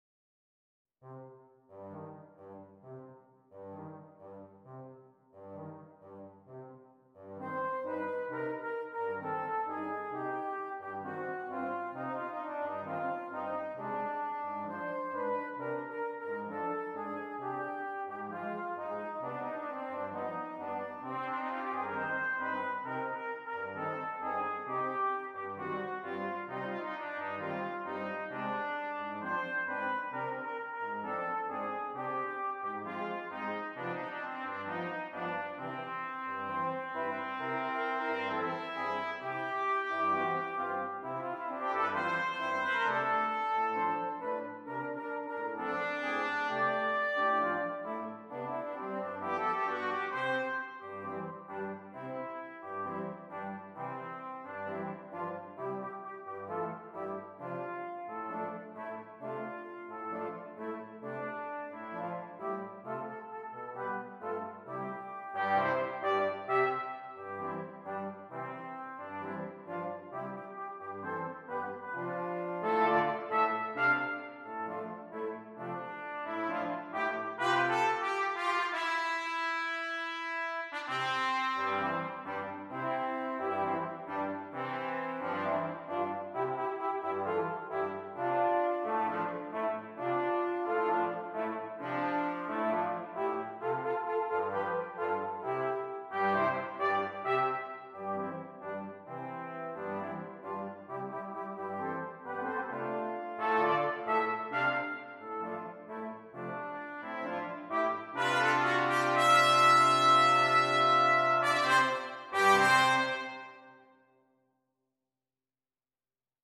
Melody passes between the various voices.